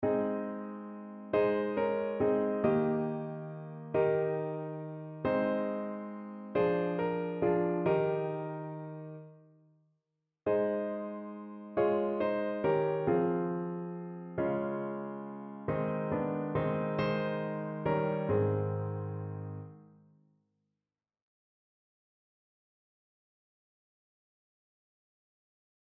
Evangeliumslieder
Notensatz (4 Stimmen gemischt)